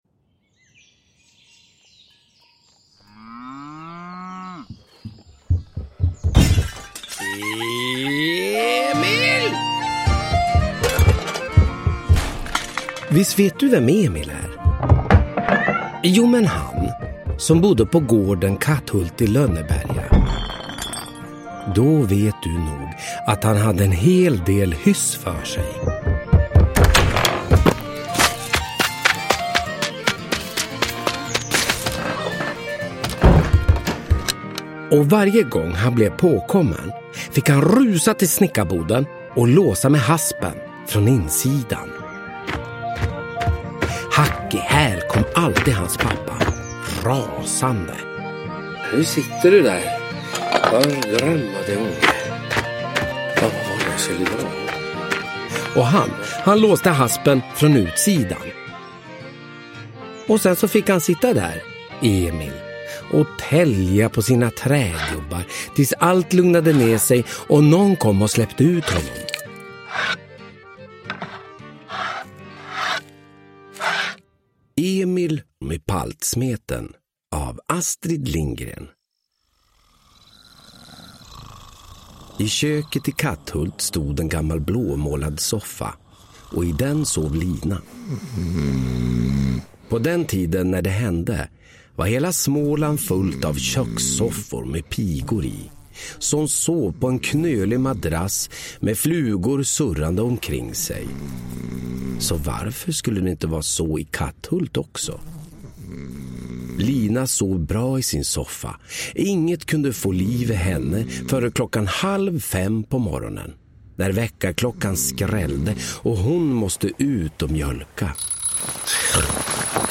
Ny inläsning av Emil i Lönneberga med stämningsfull ljudläggning!
Uppläsare: Olof Wretling